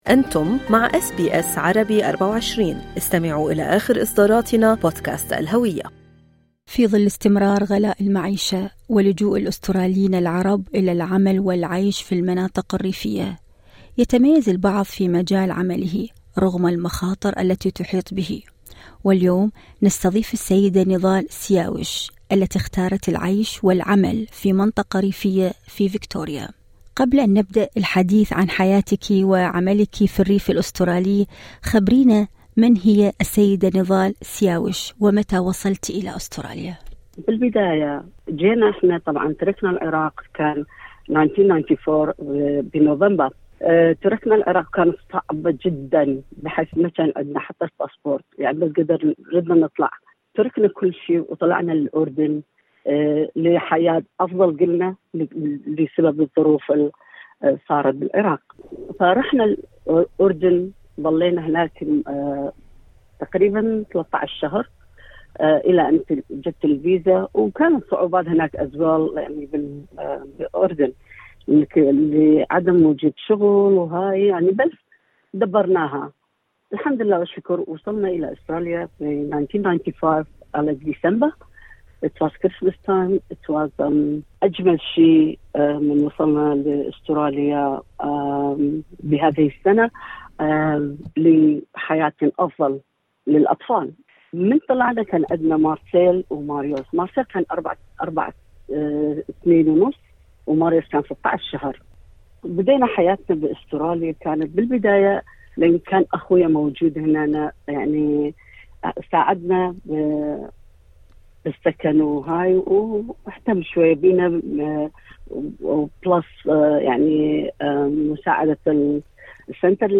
كل التفاصيل في المقابلة الصوتية أعلاه.